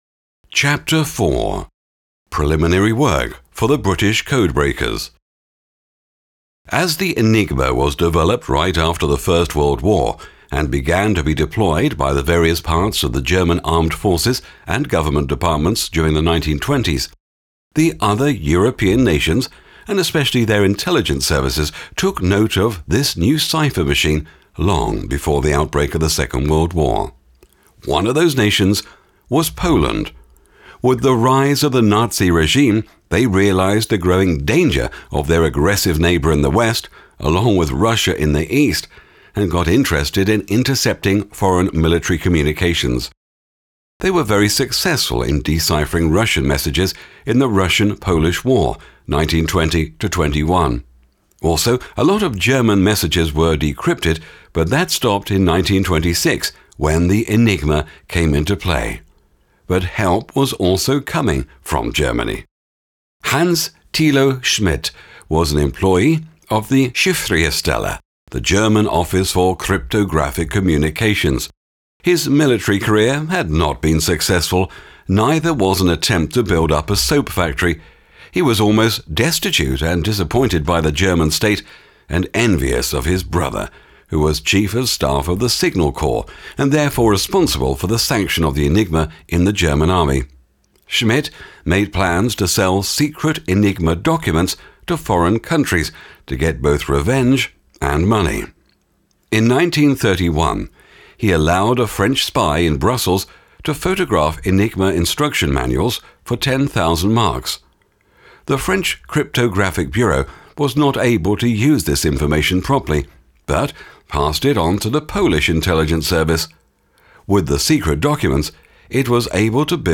Audiobook
You like a British accent?